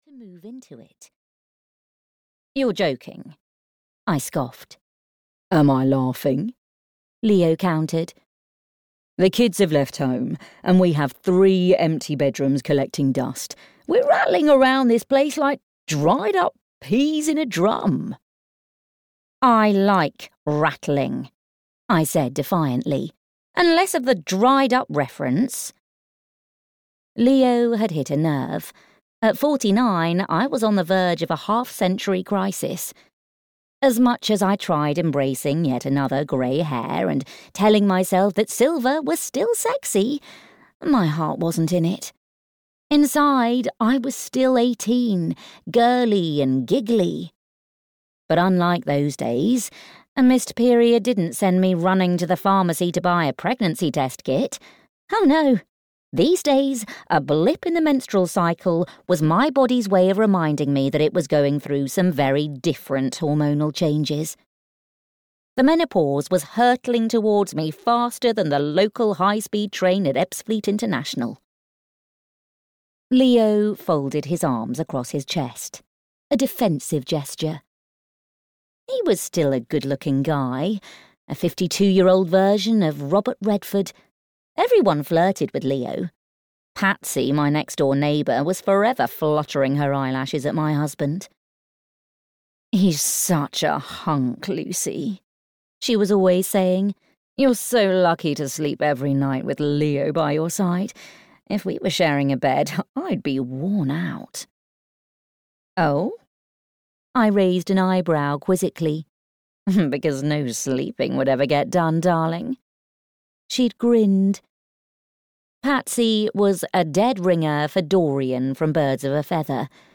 Lucy's Last Straw (EN) audiokniha
Ukázka z knihy